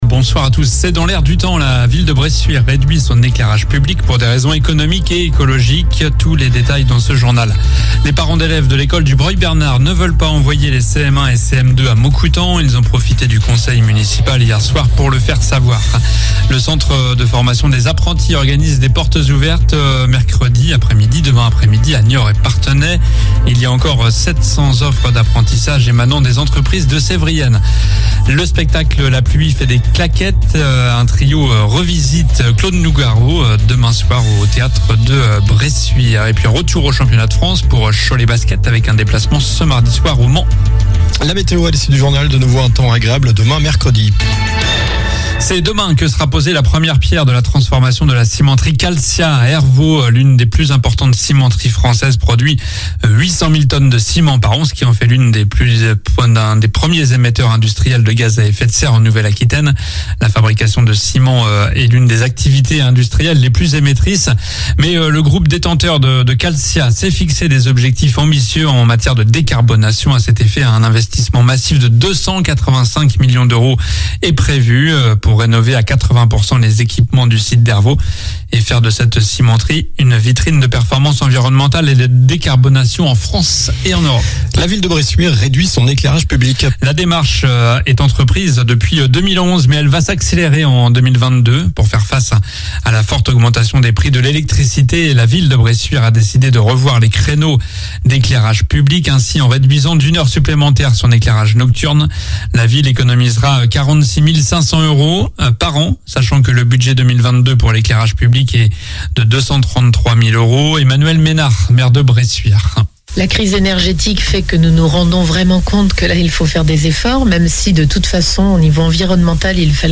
Journal du mardi 04 octobre (soir)